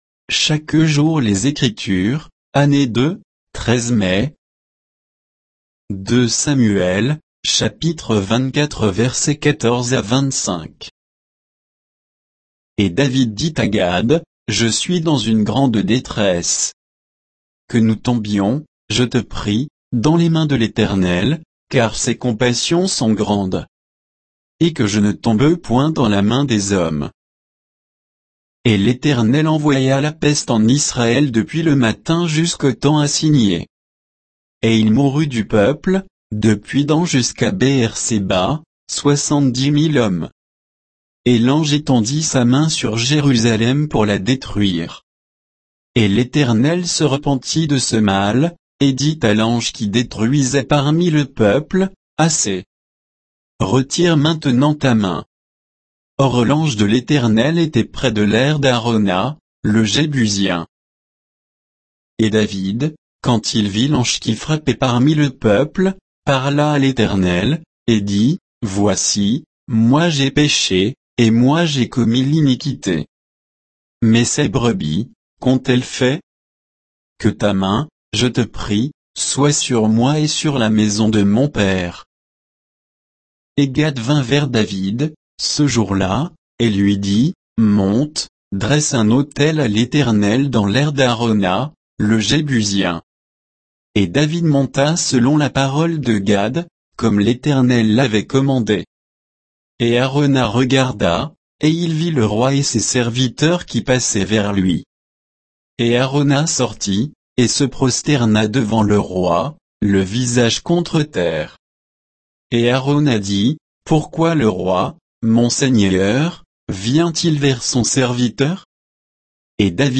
Méditation quoditienne de Chaque jour les Écritures sur 2 Samuel 24